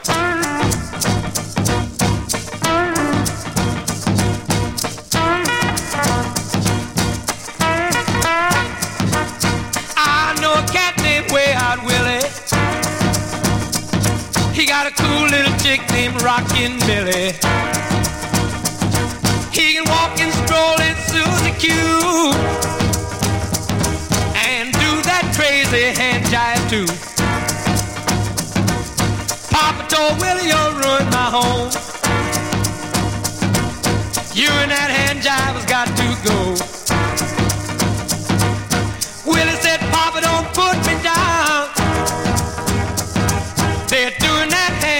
Rhythm & Blues, Rock 'N' Roll　UK　12inchレコード　33rpm　Stereo